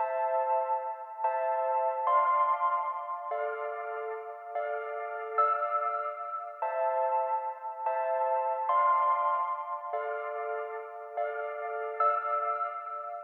Billion Benz_Pluck.wav